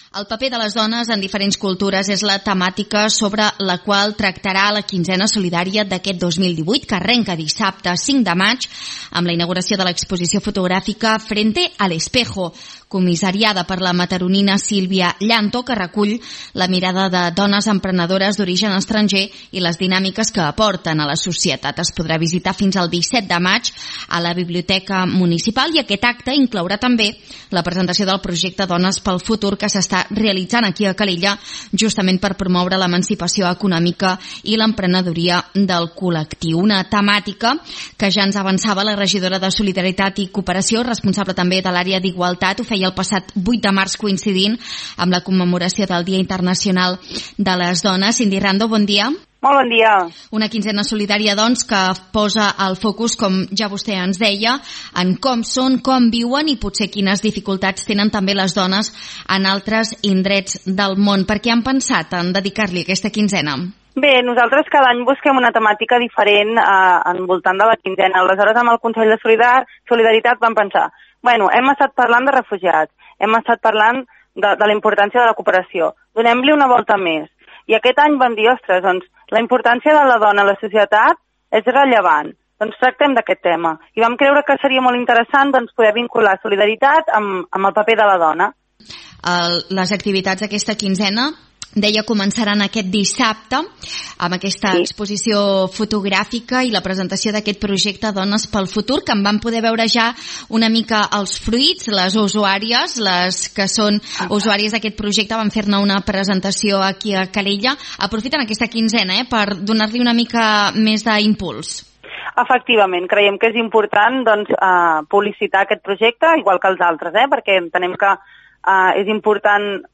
A continuació pots recuperar l’entrevista a la regidora de Solidaritat i Cooperació de l’Ajuntament de Calella a l’Info Migdia.